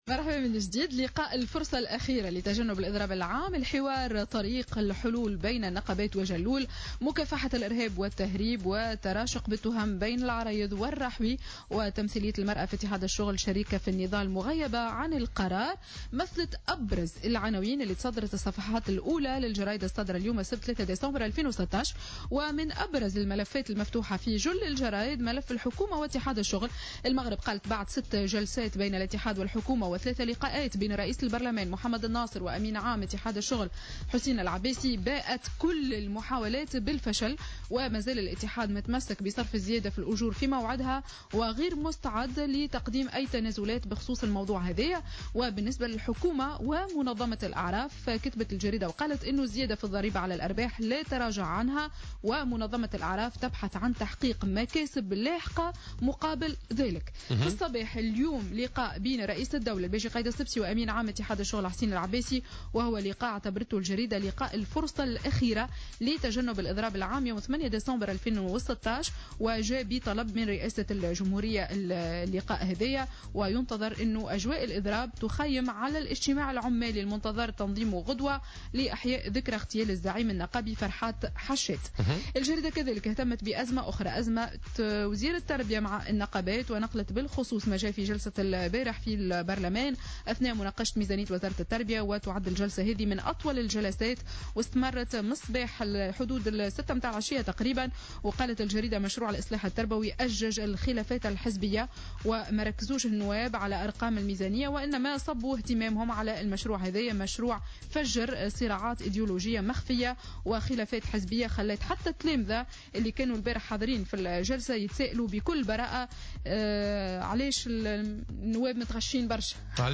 Revue de presse du samedi 3 Décembre 2016